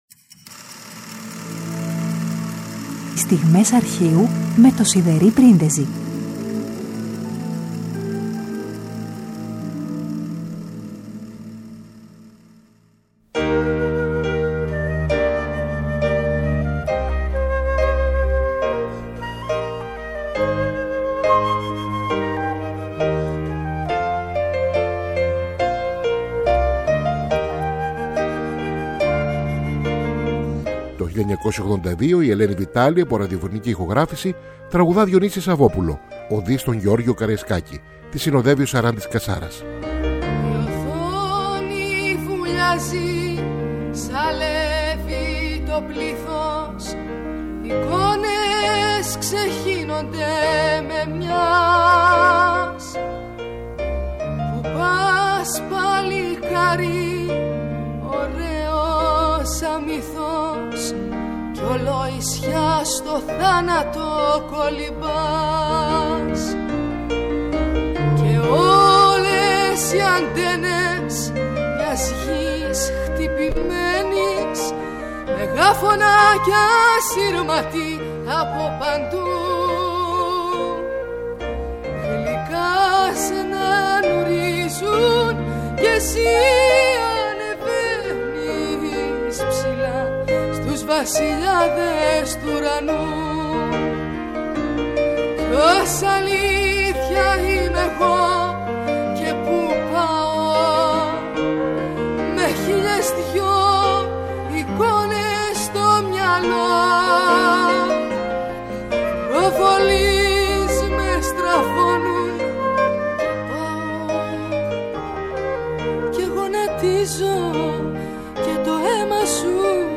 τραγούδια ηχογραφημένα στο ραδιόφωνο
πιάνο